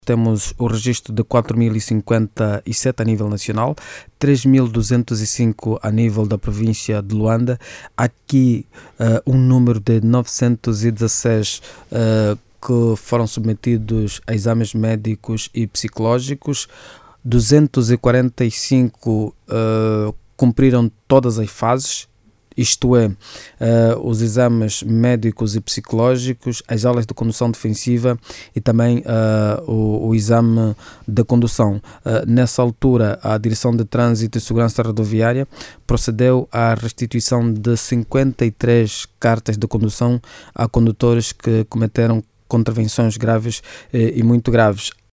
O Chefe de Departamento de Transgressões e Acidentes de Viação, Superintendente João de Sousa, diz que Luanda tem mais de três mil condutores infractores.